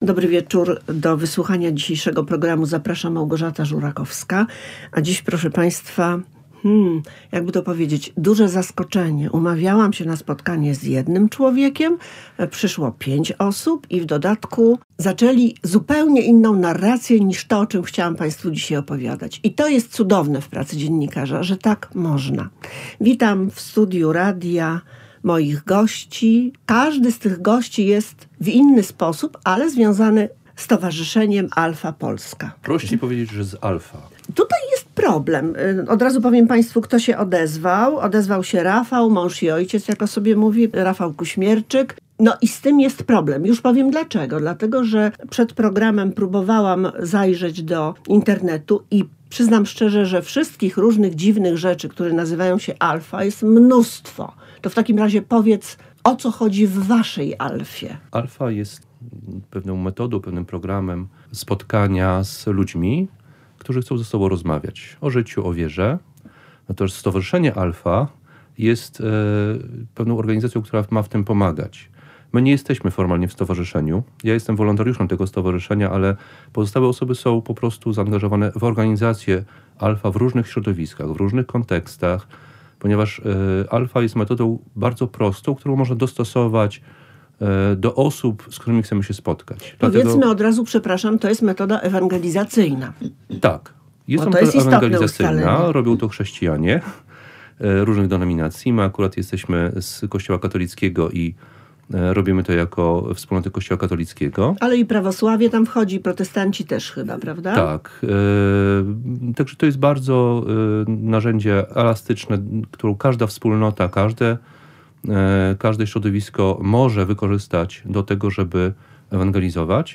W studiu będą o tym opowiadać uczestnicy spotkań i wolontariusze pracujący jako pomocnicy, prowadzący kursy czy organizujący zaplecze.